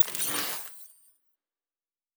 pgs/Assets/Audio/Sci-Fi Sounds/Electric/Device 10 Start.wav at master